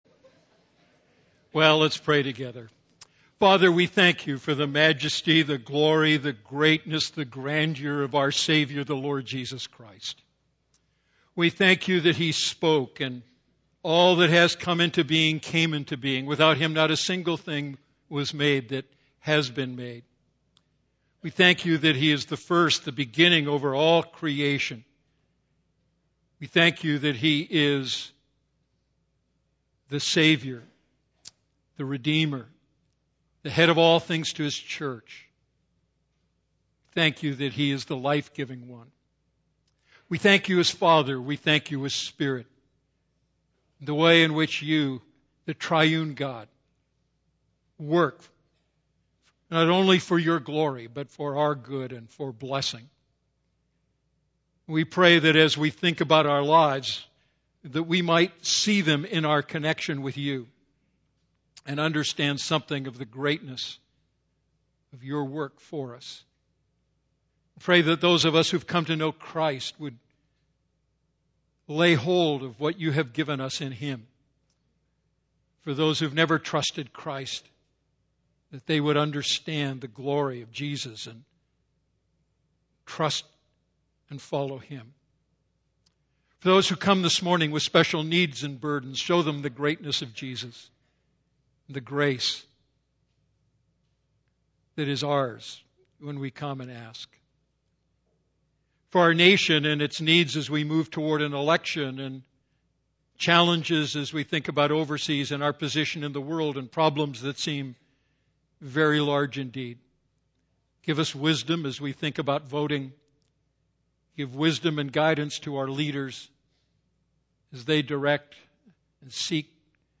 A message from the series "Going for the Gold."